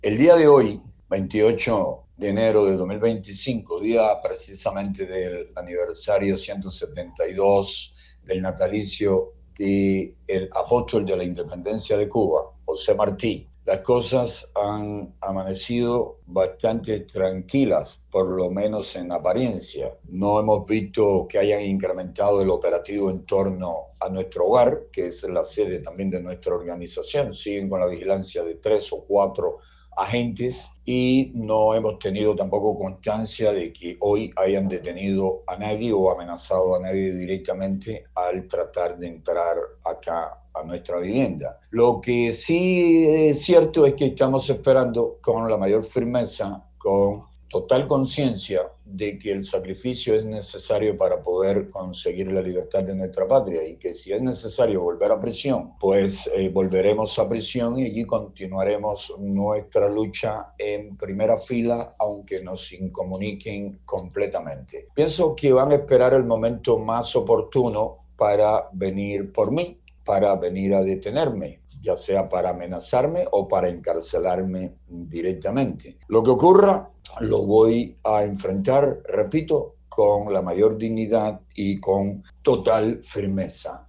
Declaraciones de José Daniel Ferrer a Martí Noticias